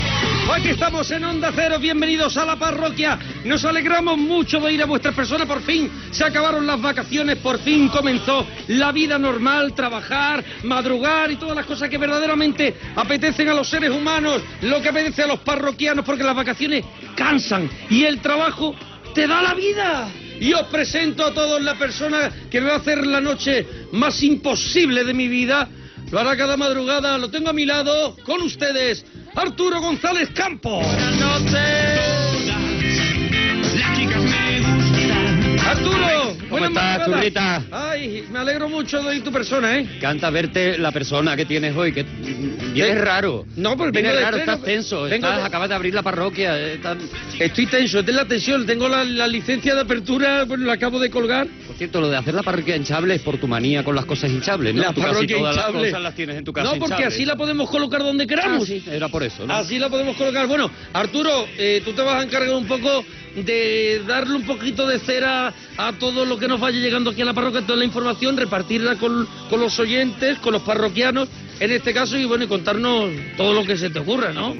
Careta i inici del programa.
Entreteniment